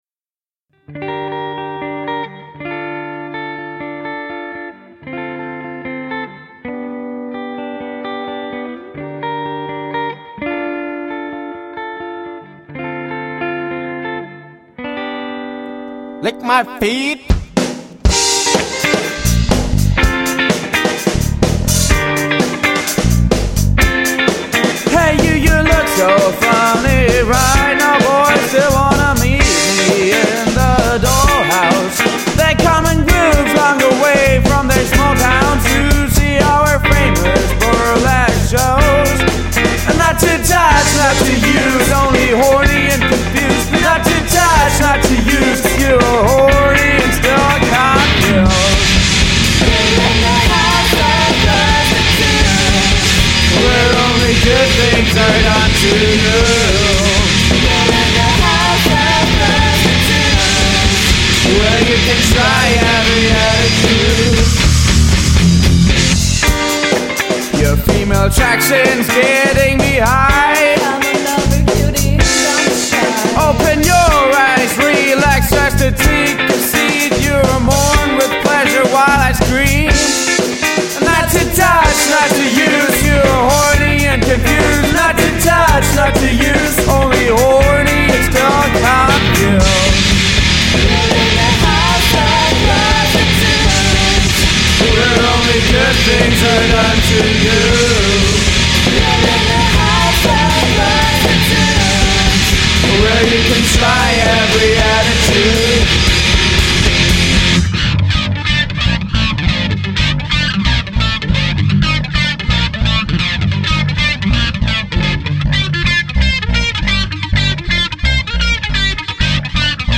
Vocals / Bass
Guitar
Drums